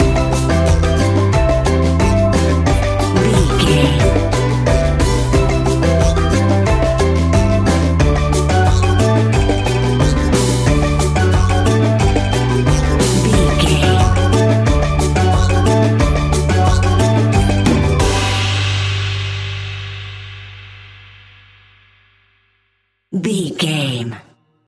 A groovy and upbeat piece of island summer sunshine music.
That perfect carribean calypso sound!
Uplifting
Ionian/Major
calypso music
steel pan
drums
percussion
bass
brass
guitar